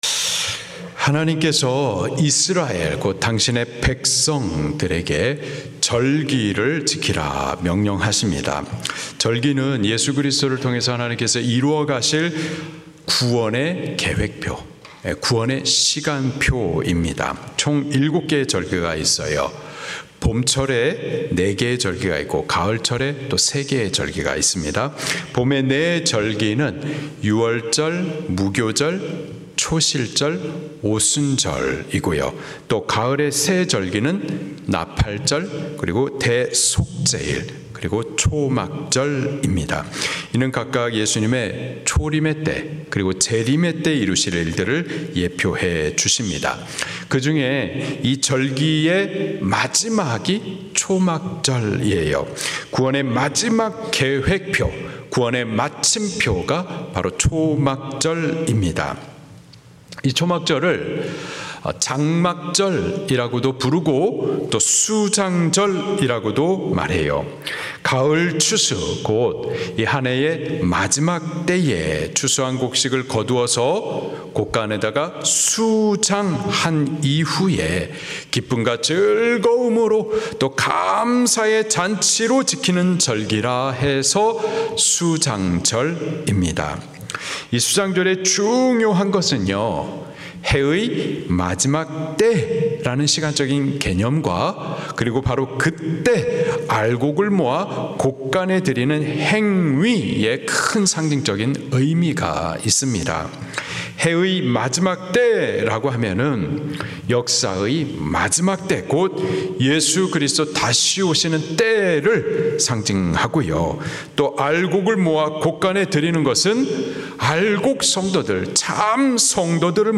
설교
주일예배